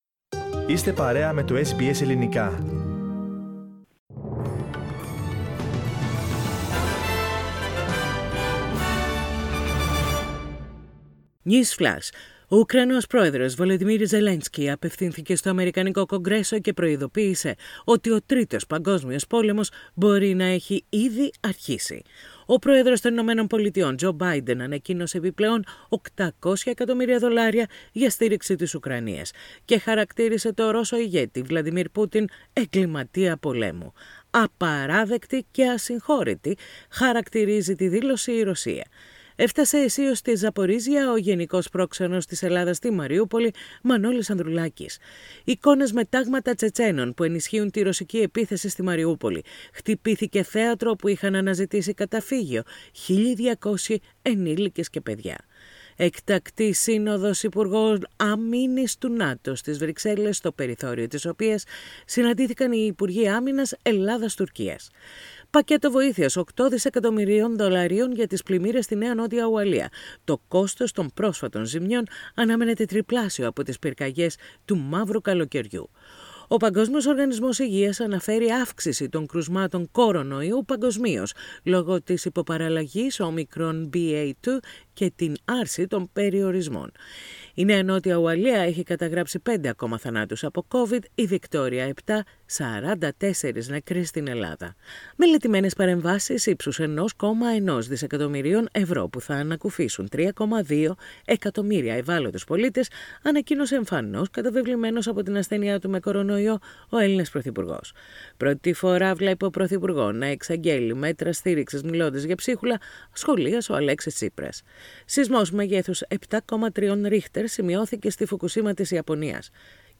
News in Greek.